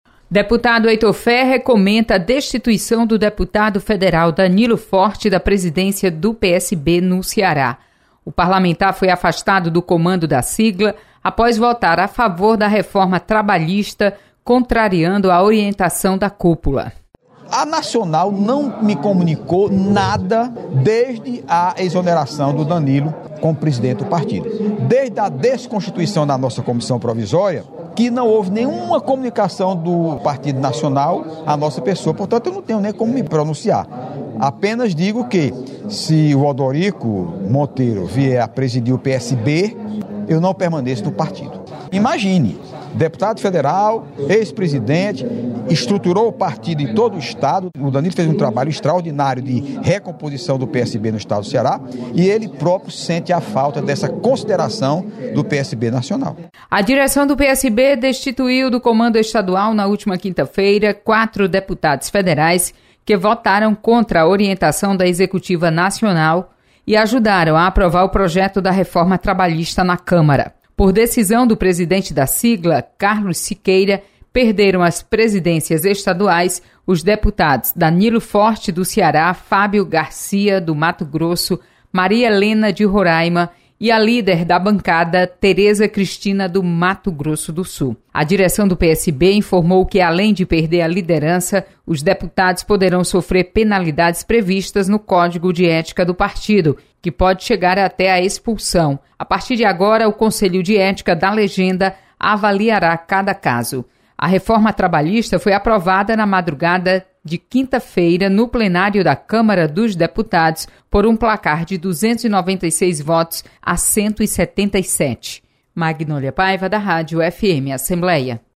Deputado Heitor Férrer comenta destituição de Danilo Forte da Presidência do PSB no Ceará.